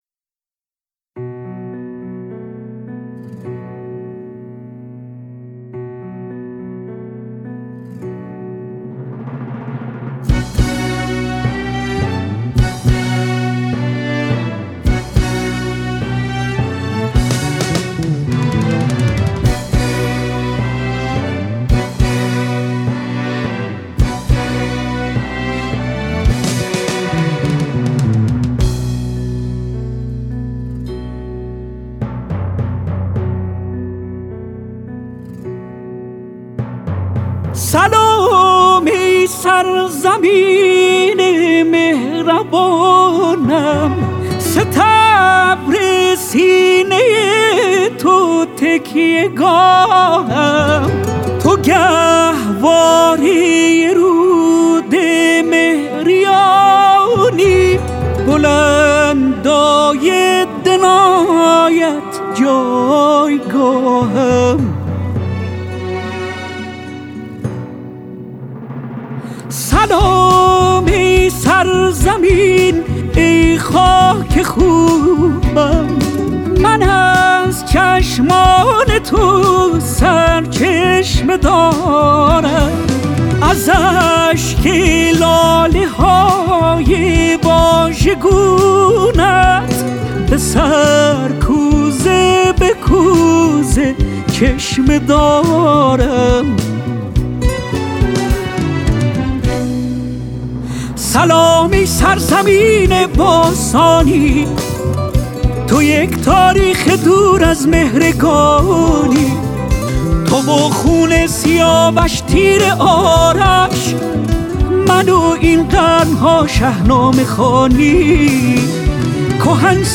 خواننده جوان و خوش صدای پاپ